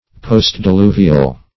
Search Result for " postdiluvial" : The Collaborative International Dictionary of English v.0.48: Postdiluvial \Post`di*lu"vi*al\, Postdiluvian \Post`di*lu"vi*an\, a. [Pref. post- + diluvial, diluvian.] Being or happening after the flood in Noah's days.